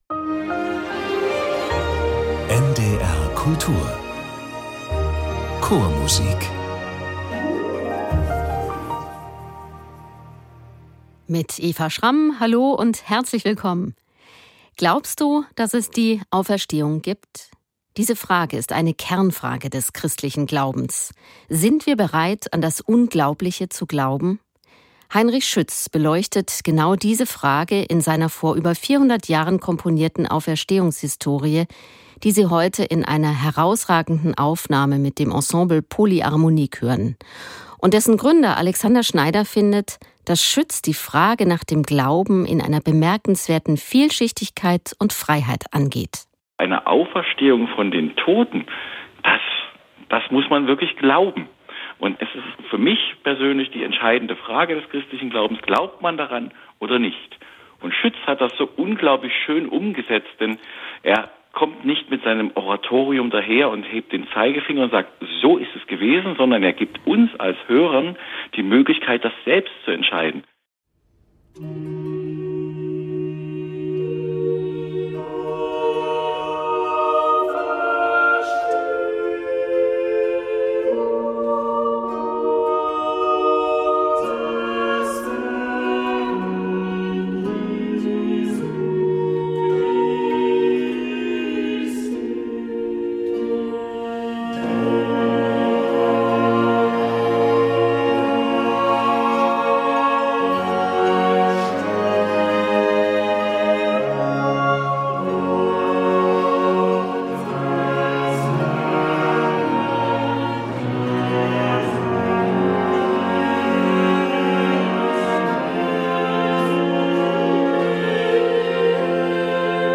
Das Ensemble Polyharmonique präsentiert eine sinnliche und plastische Interpretation von Schütz‘ erstem Oratorium.